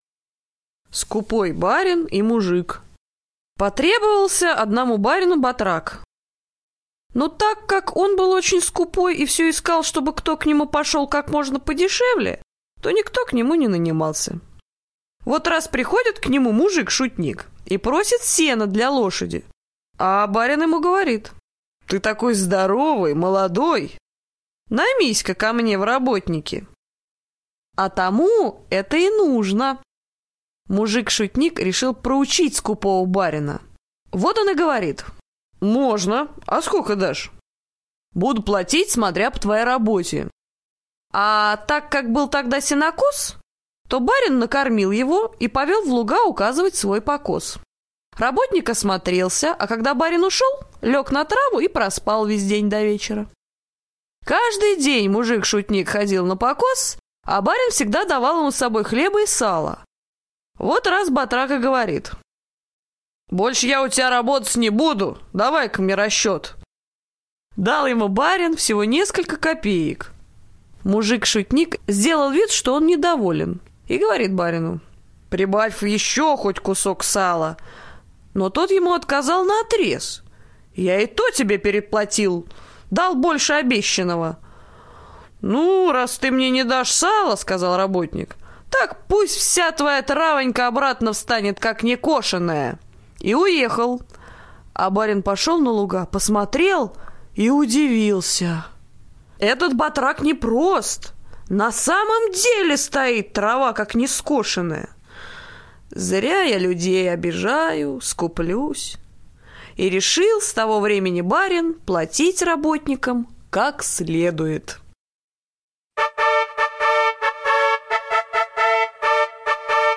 Скупой барин и мужик - латышская аудиосказка. Барин был очень скупой. Понадобился ему работник и он искал такого, чтобы ему мало заплатить.